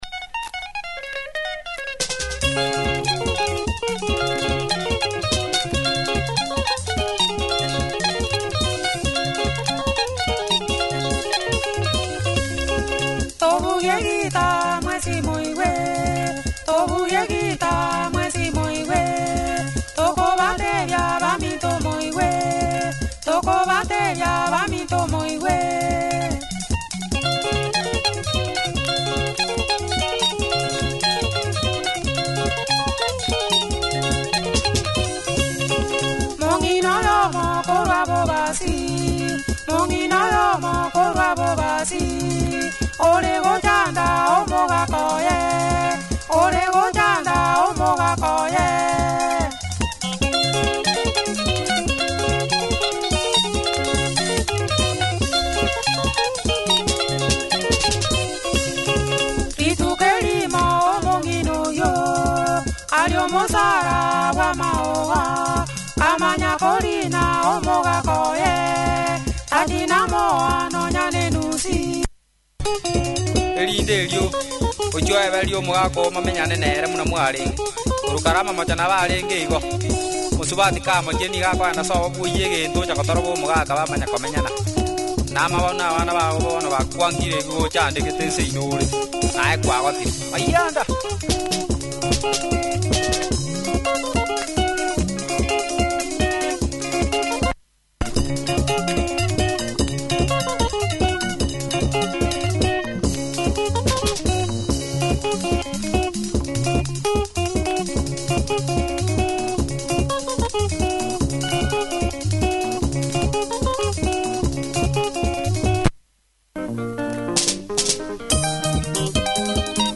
Tight Kisii Benga, punchy production good tempo, loud too.